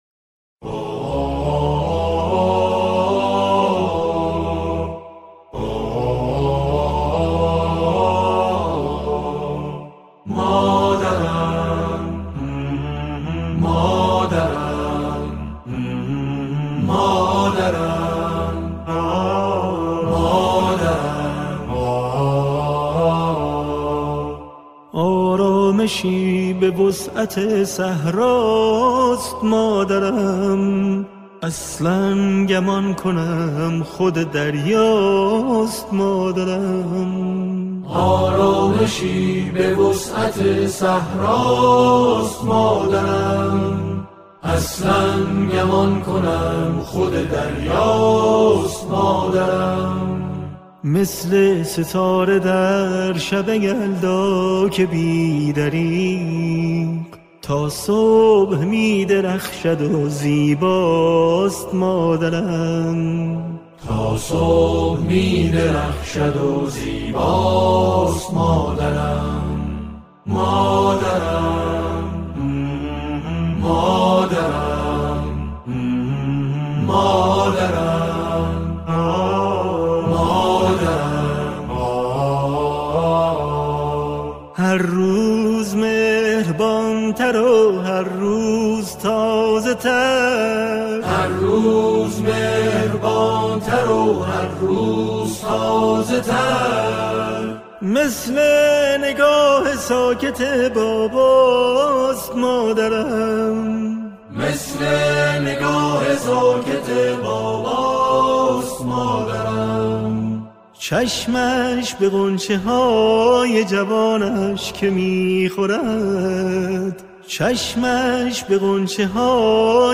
سرودهای حضرت فاطمه زهرا سلام الله علیها